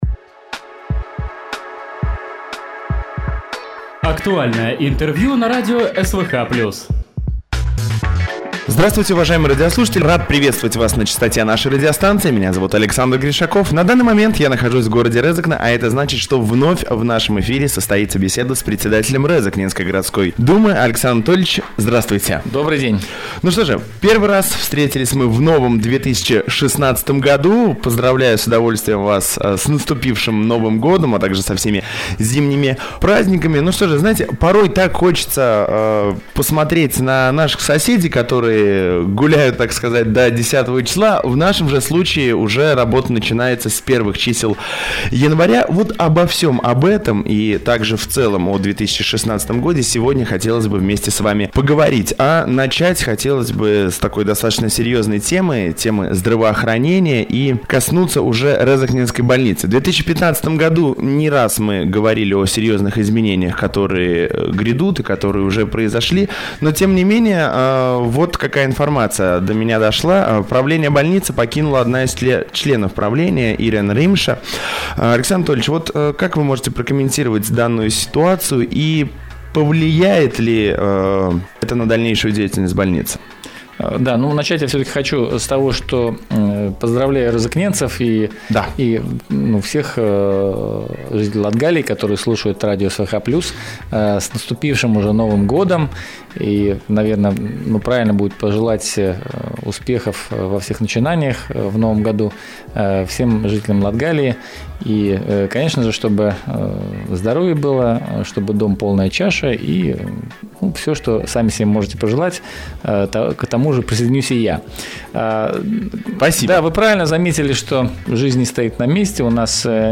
Гость в студии (13.01.16.)
Актуальная информация о происходящем в Резекне из уст председателя самоуправления